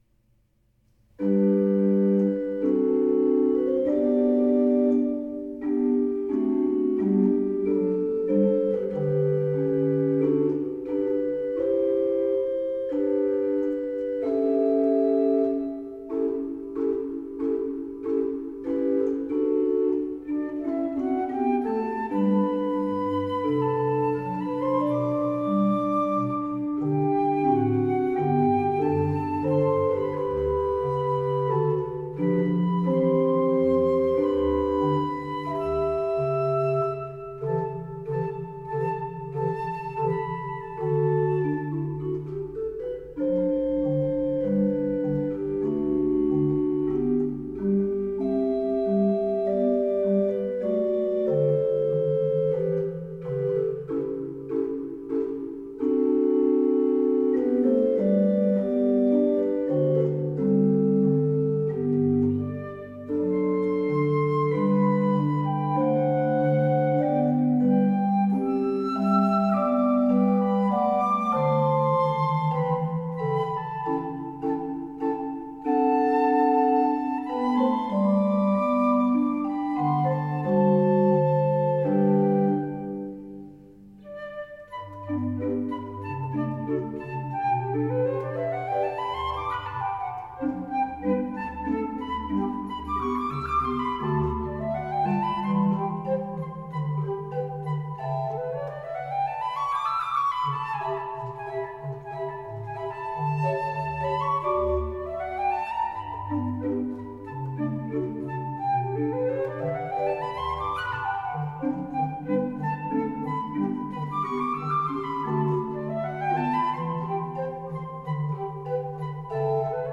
Aus der Serenade op. 41 für Flöte und Orgel